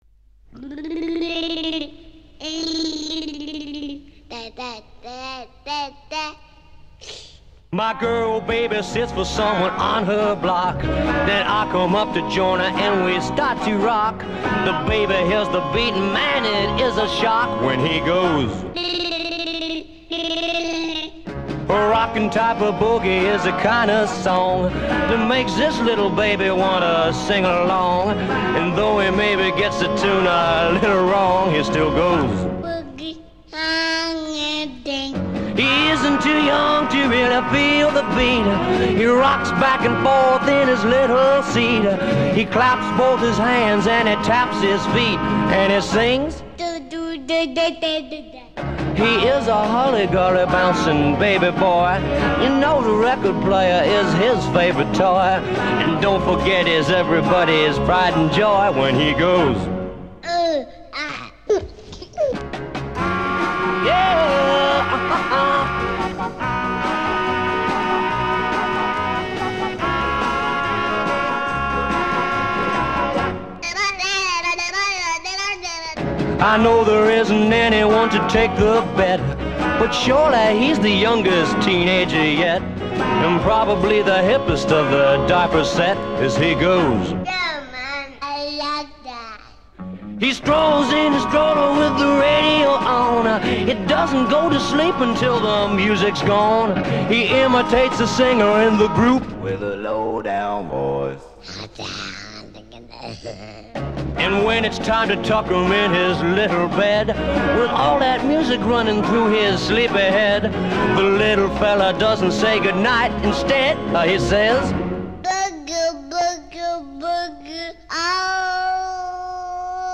donde se oye cantar a un bebé